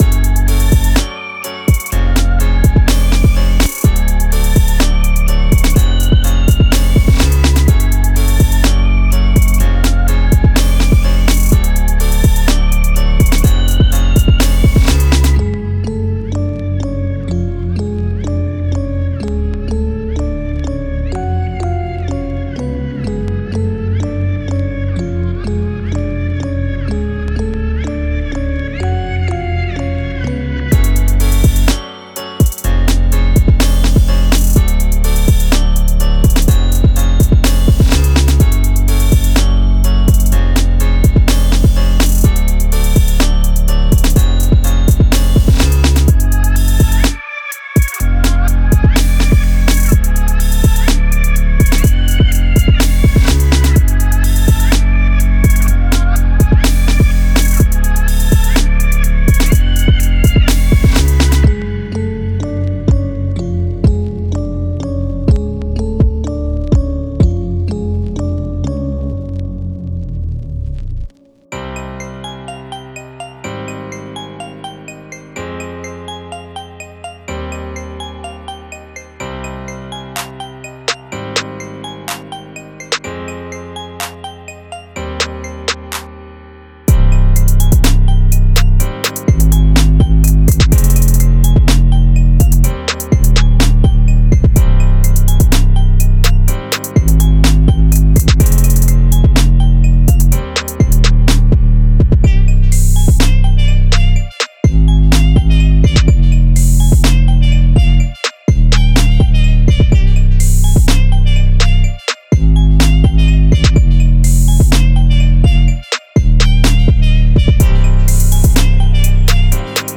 Hip HopTrap
用巨大的808机折磨您的潜水艇，并用令人垂涎的帽子和圈套三胞胎来设定音调，所有这些设定都在困扰和催眠的合成器之中。